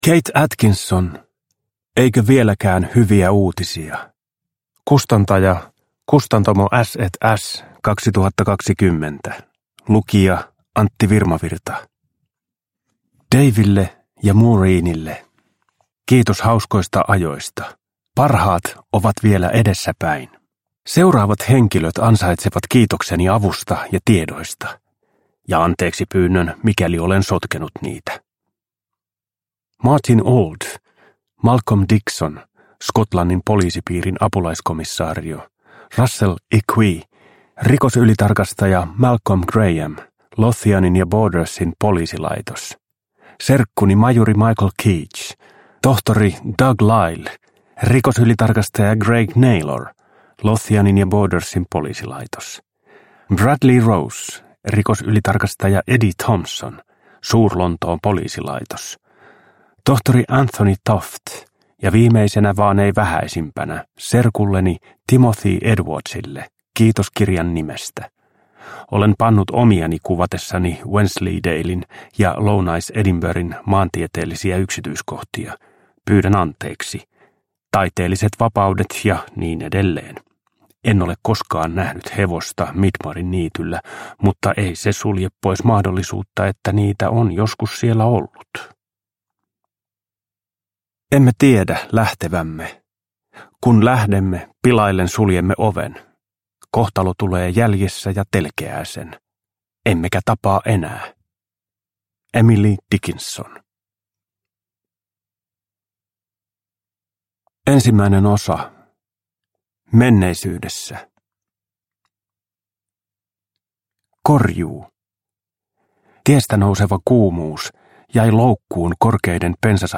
Eikö vieläkään hyviä uutisia? – Ljudbok – Laddas ner
Uppläsare: Antti Virmavirta